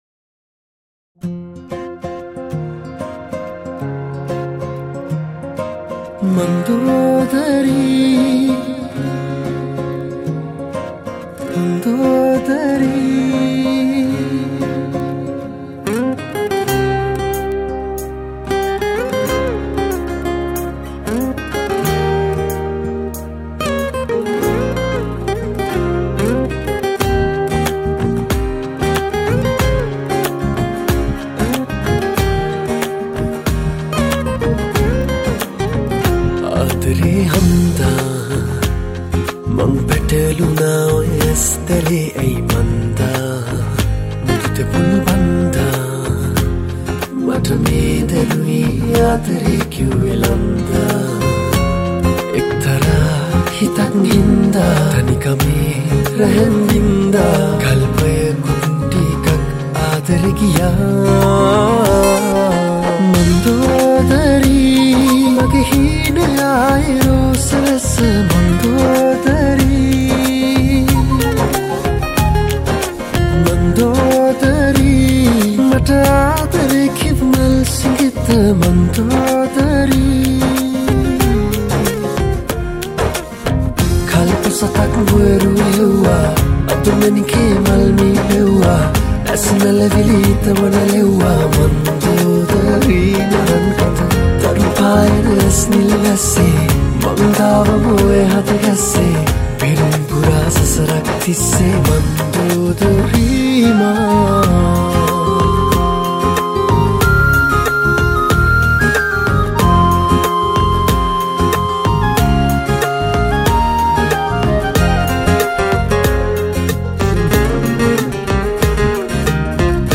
Category: Teledrama Song